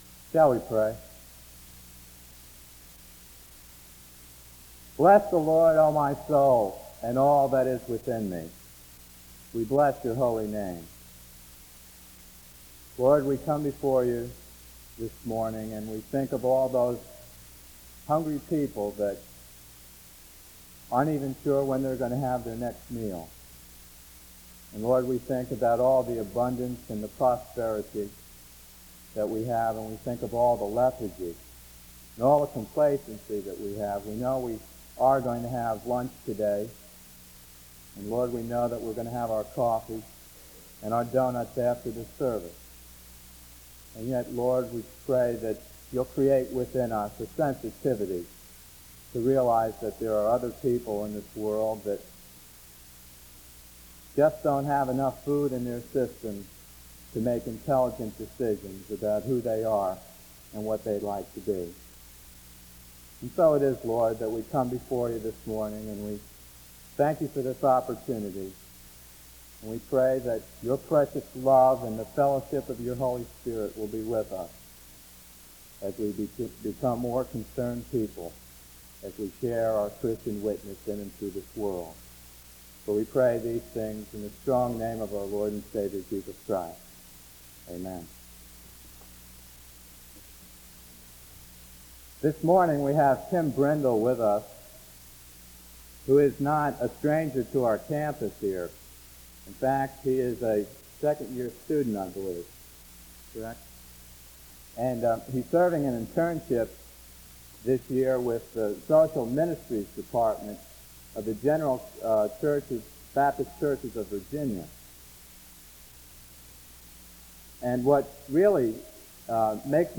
The service starts with a prayer from 0:00-1:28. An introduction to the speaker is given from 1:32-3:02.
Southeastern Baptist Theological Seminary